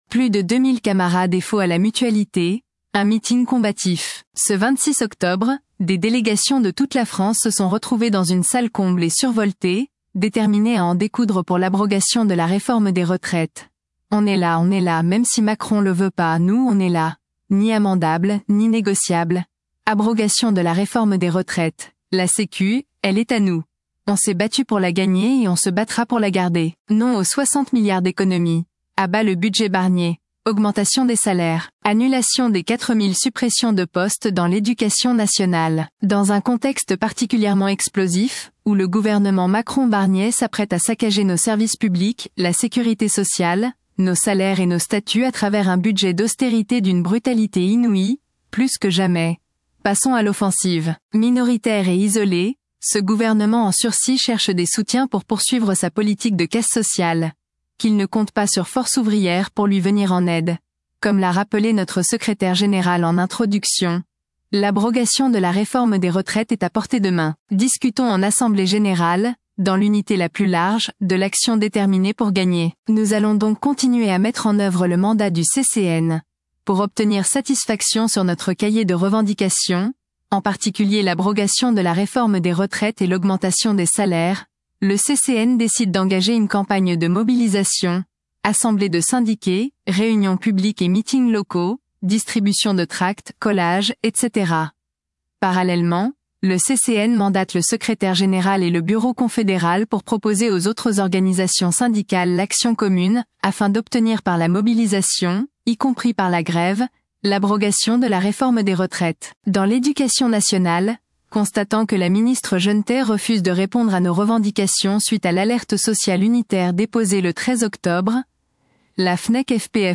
Plus de 2000 camarades FO à la Mutualité : un meeting combatif !
Ce 26 octobre, des délégations de toute la France se sont retrouvées dans une salle comble et survoltée, déterminés à en découdre pour l’abrogation de la réforme des retraites : « On est là, on est là, même si Macron le veut pas, nous on est là ! Ni amendable, ni négociable ! Abrogation de la réforme des retraites ! La Sécu, elle est à nous ! On s’est battus pour la gagner et on se battra pour la garder ! »